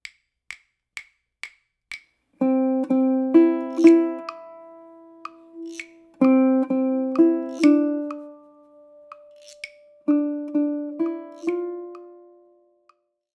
Thus, in the example below, the whole note (4 beats) is tied to a quarter note (1 beat), yielding a 5 beat sustain: 4 + 1 = 5.
Tie Example | Ties in the Banks of the Ohio.